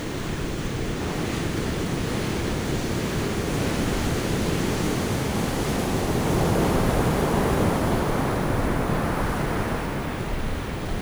sonomaCoast.wav